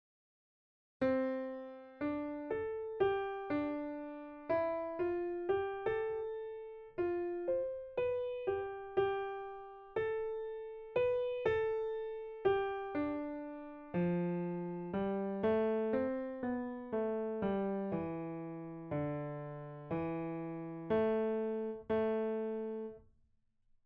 Exercise 9 – Piano
Pozzoli_1_Example9_piano.mp3